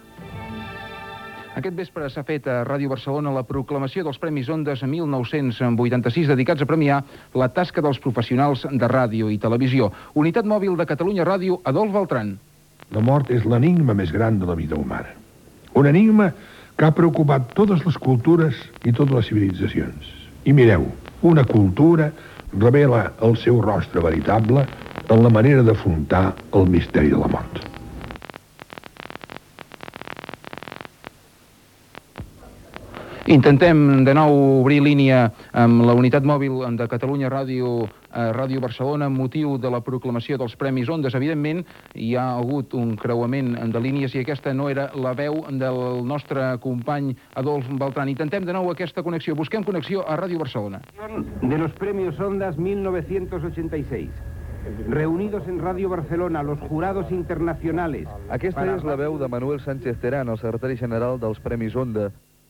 Connexió amb Ràdio Barcelona per informar del veredicte dels Premios Ondas 1986, amb una errada tècnica
Informatiu
FM